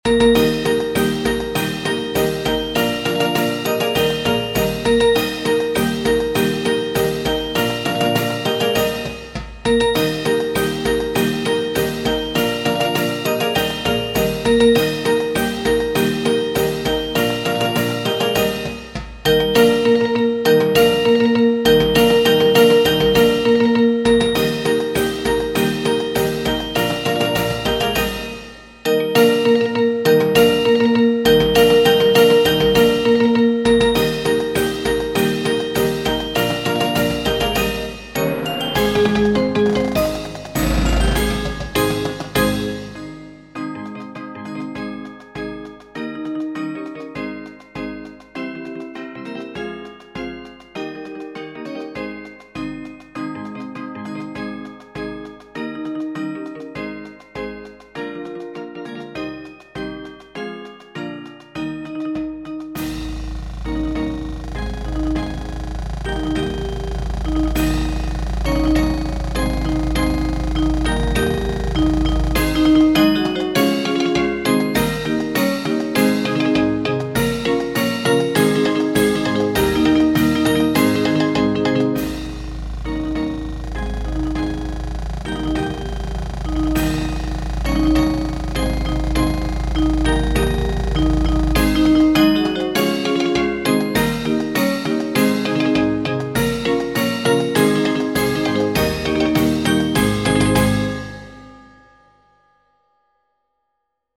steel drums. The lineup now is as follows: 3 glockenspiels 3 sets of crotales 2 xylophones Tubular bells 4 vibraphones 7 marimbas (2 grand staff, 2 treble clef, 3 bass clef) Timpani Battery percussion Soprano steel drums Alto steel drums Tenor steel drums Bass steel drums I also did this because my portions of my family history are a mix of German and Swedish.